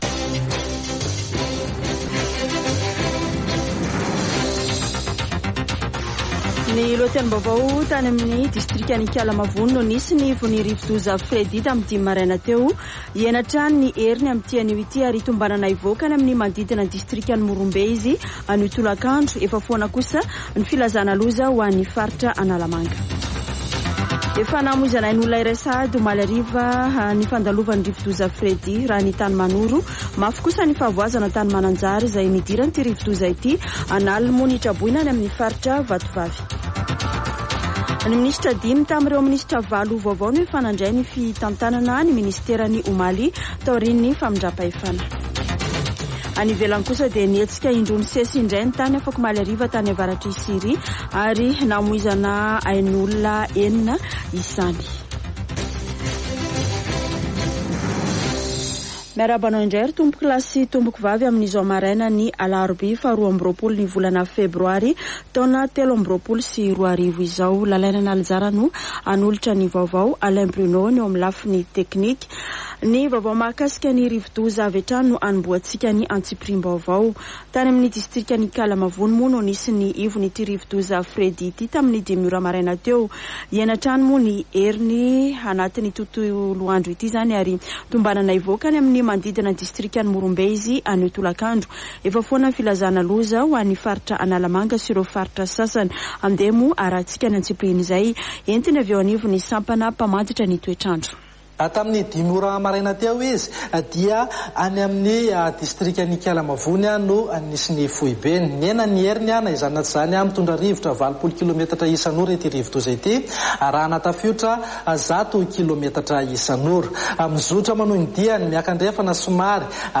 [Vaovao maraina] Alarobia 22 febroary 2023